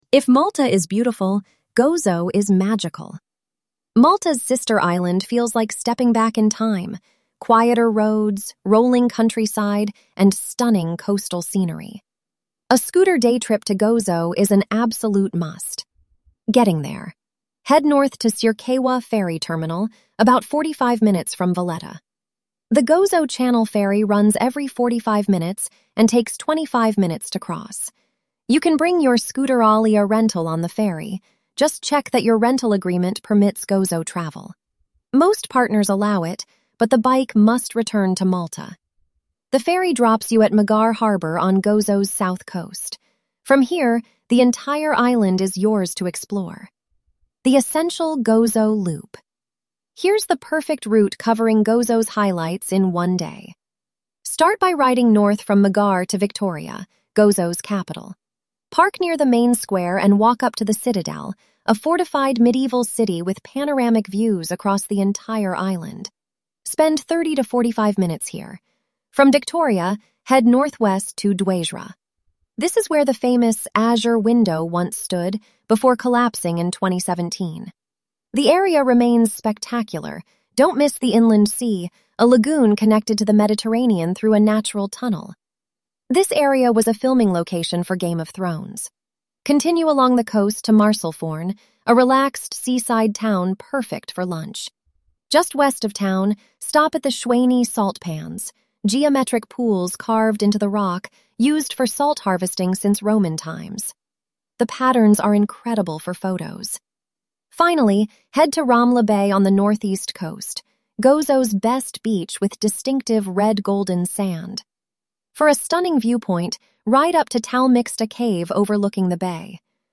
🎧 Malta Scooter Riding Audio Guide